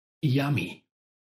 Yummy мужской